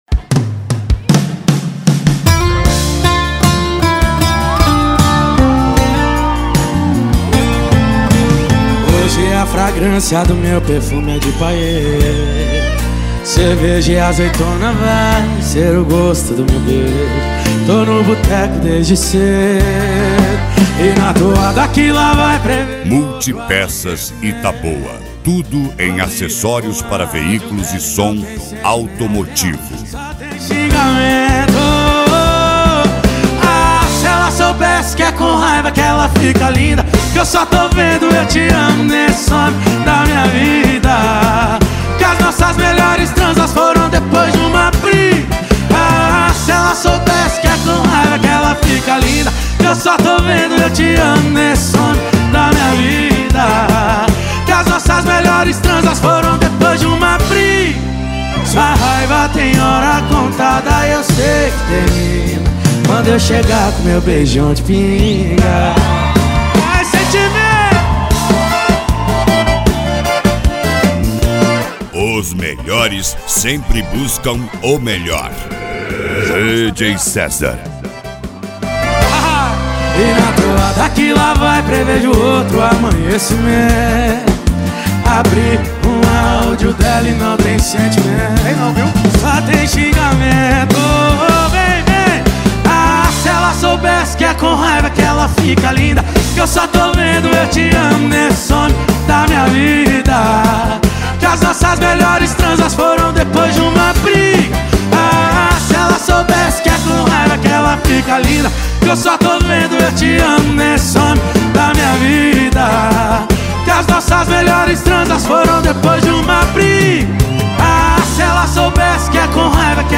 Moda de Viola
Modao
SERTANEJO
Sertanejo Raiz
Sertanejo Universitario